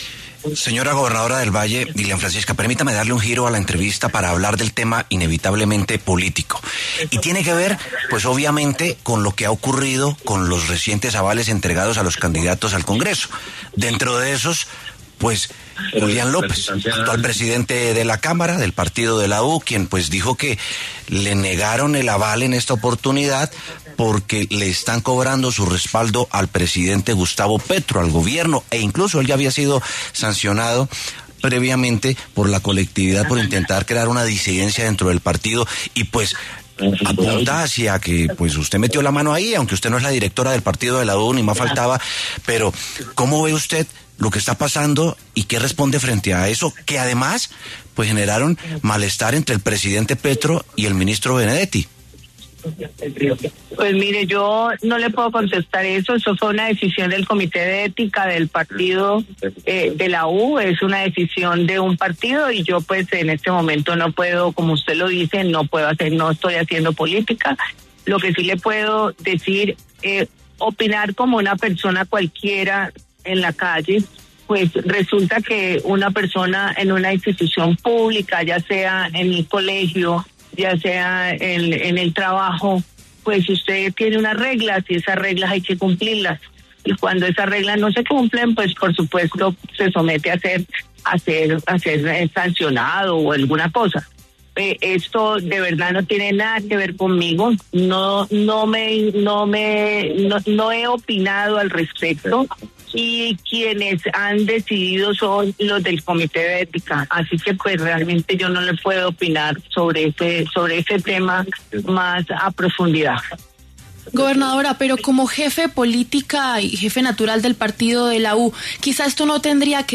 En los micrófonos de La W, con Julio Sánchez Cristo, habló la gobernadora del Valle, Dilian Francisca Toro, sobre la polémica política que se ha desatado en el Partido de la U, luego de que esta colectividad le negara el aval a Julián López, presidente de la Cámara, para participar en las elecciones legislativas de 2026.